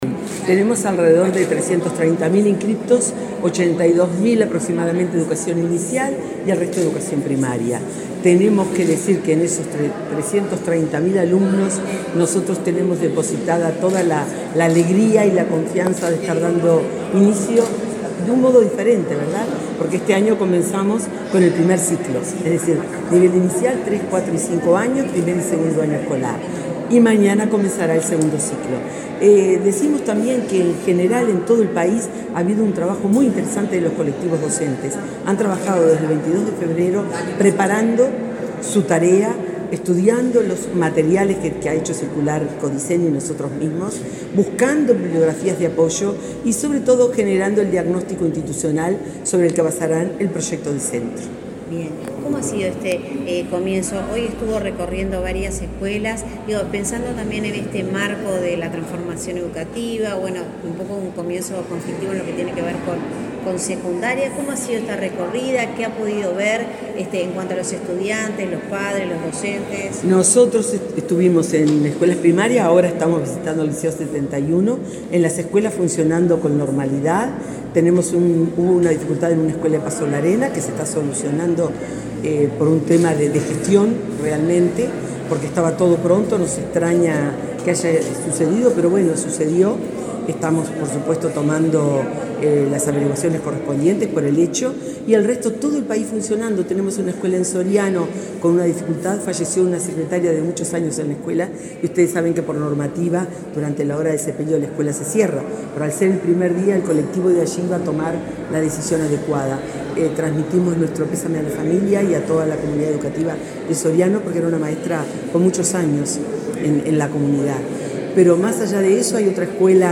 Declaraciones de la directora de Primaria, Graciela Fabeyro
La directora de Primaria, Graciela Fabeyro, dialogó con la prensa, luego de recorrer varios centros educativos este lunes 6, por el inicio del año